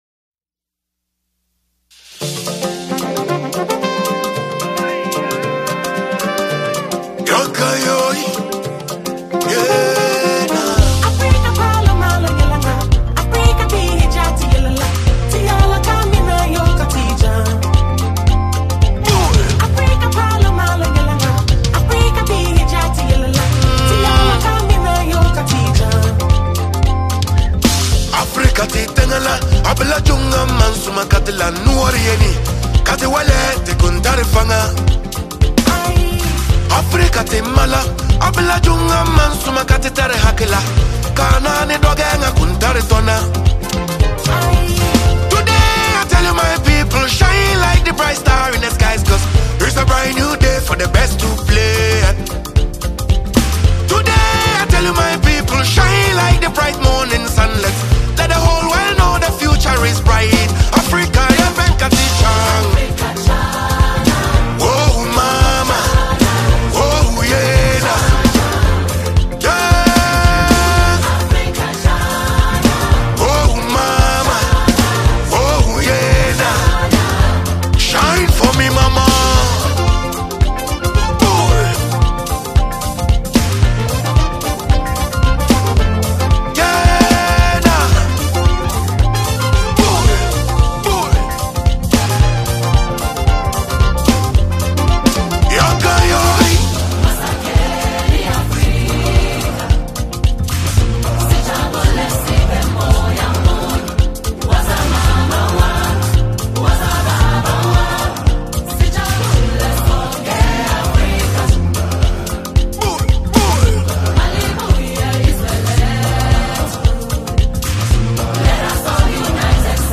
Highly-rated Ghanaian reggae-dancehall musician